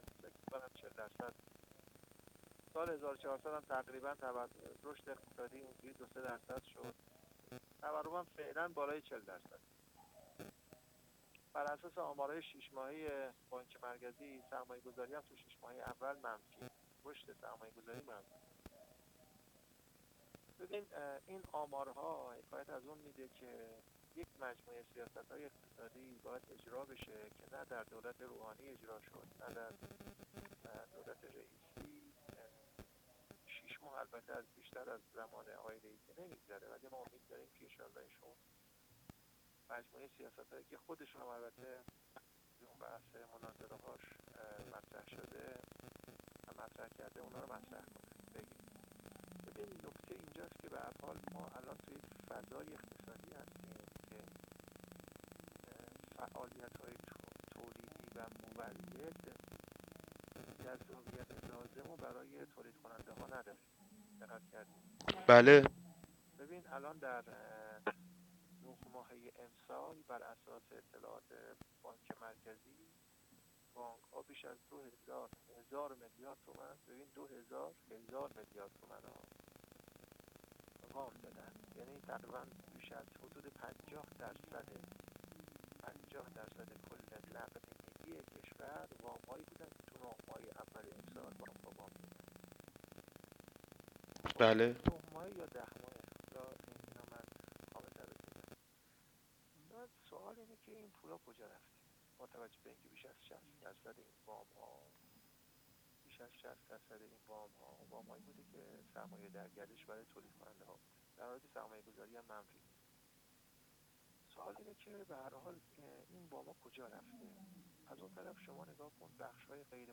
حسین صمصامی، سرپرست پیشین وزارت اقتصاد و استادیار دانشگاه شهید بهشتی در گفت‌وگو با خبرنگار ایکنا